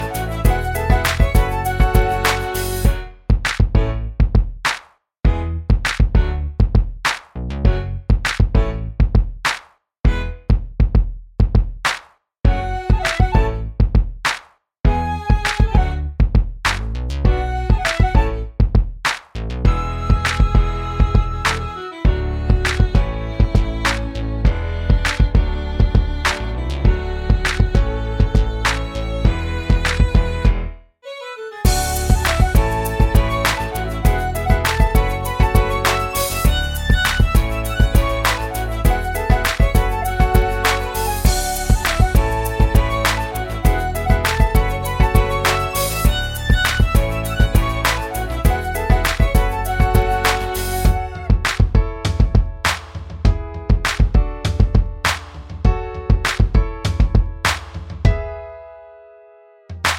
Minus Main Guitar For Guitarists 2:50 Buy £1.50